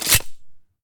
select-auto-shotgun-1.ogg